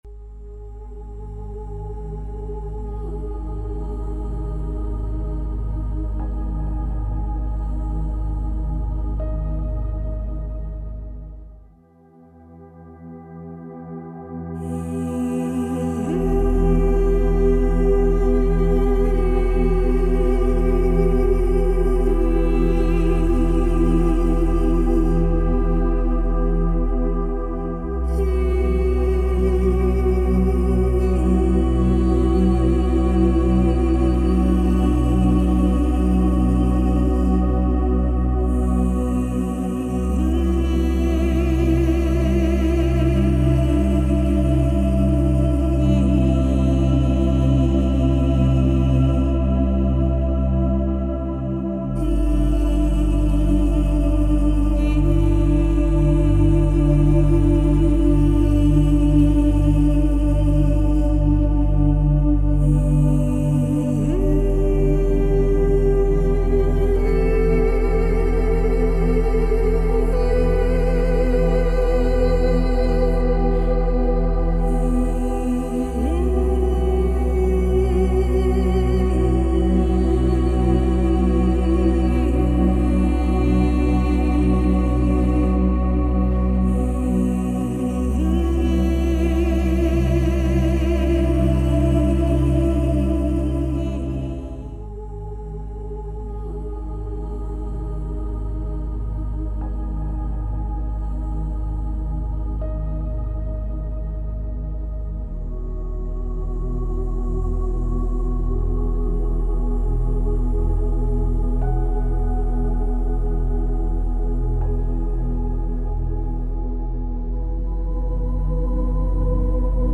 Schweigebeitrag: "Zentrierung" Dieses Video ist für dich gedacht, damit du jederzeit in deine Zentrierung zurückkehren kannst.
Konzentriere dich in den nächsten 30 Schweigeminuten auf Folgendes: